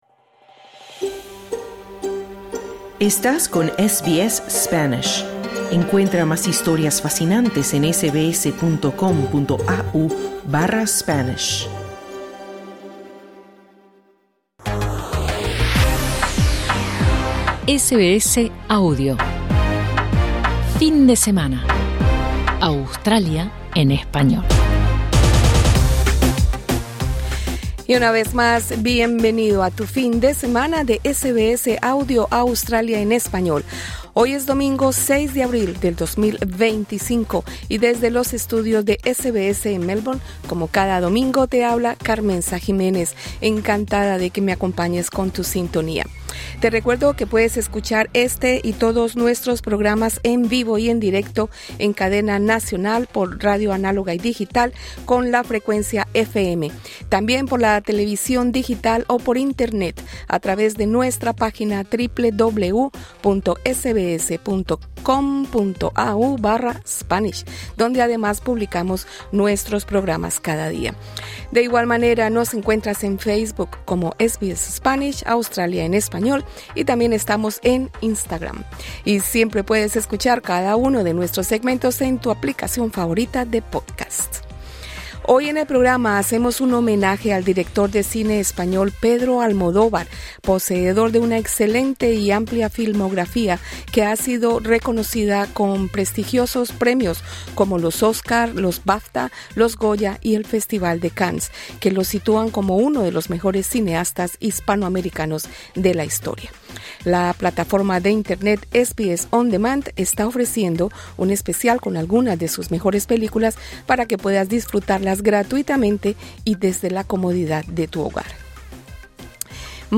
Entrevista con el cineasta